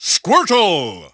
The announcer saying Squirtle's name in English releases of Super Smash Bros. Brawl.
Squirtle_English_Announcer_SSBB.wav